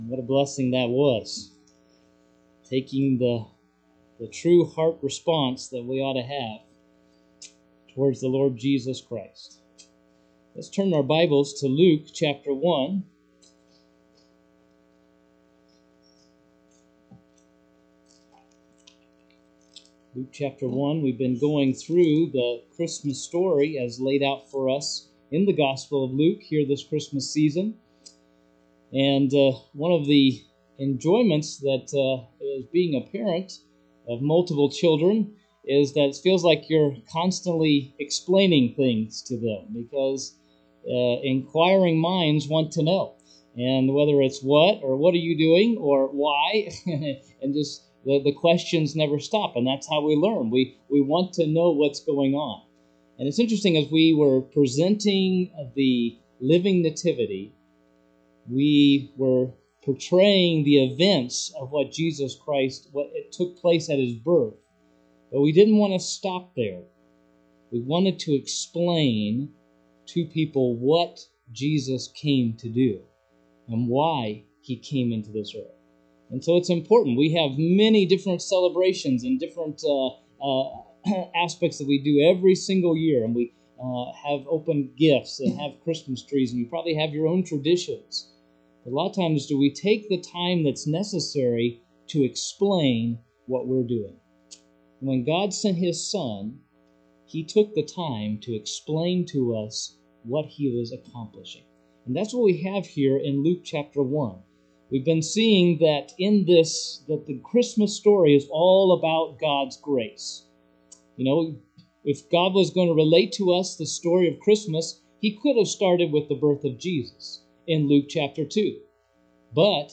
Sunday Evening
Sermons